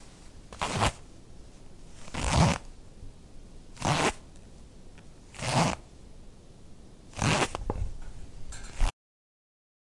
描述：裤子拉链
Tag: 衣服 拉链 服装和 - 配件 裤子 压缩和解 夹克 脱衣服 解压缩 拉链 OWI 解压缩